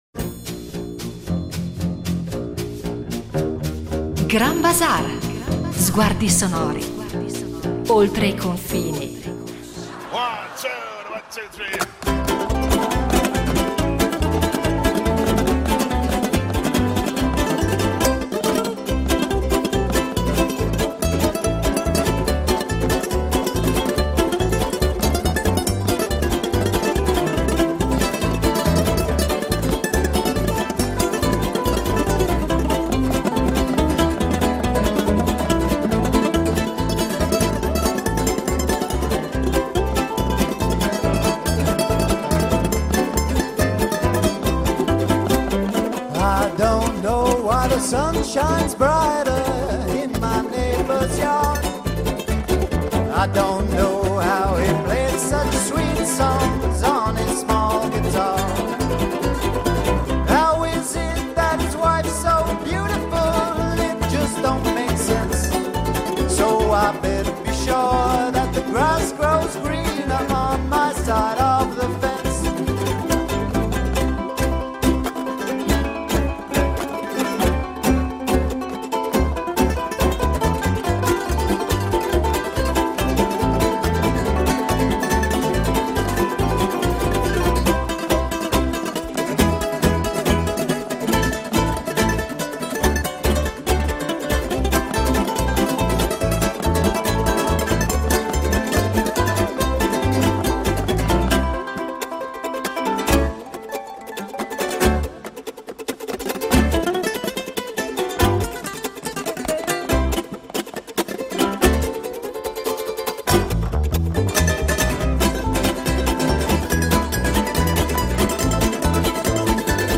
Tra racconti, curiosità, ascolti e momenti performativi, l’ukulele rivelerà tutta la sua forza espressiva: quattro corde soltanto, ma un mondo intero da scoprire.